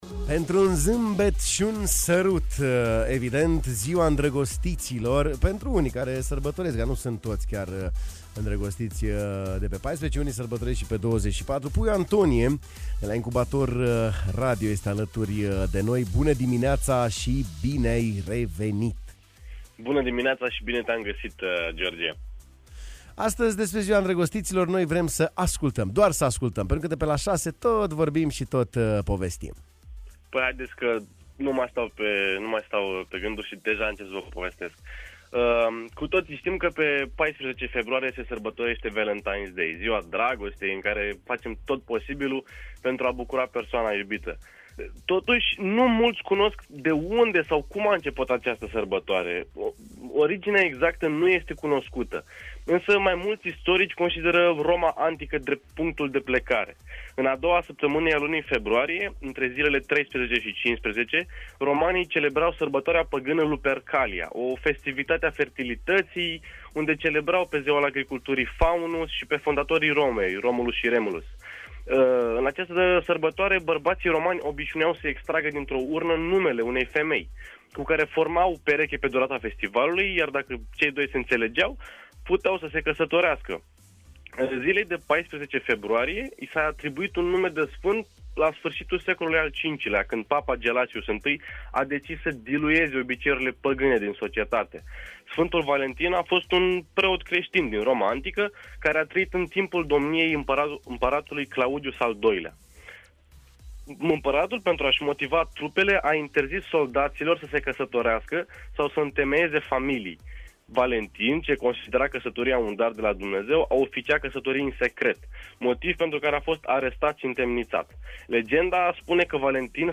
în direct la Bună Dimineața